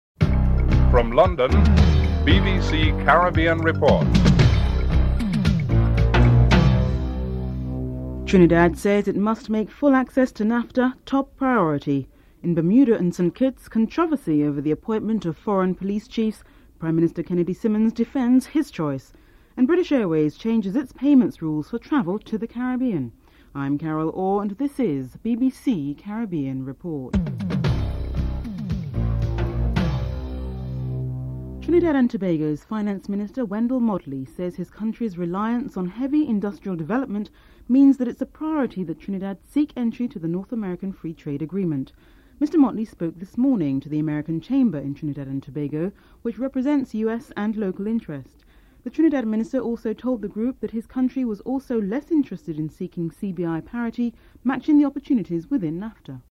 2. Report on Trinidad and Tobago's priority to seek entry into NAFTA (00:30-00:55)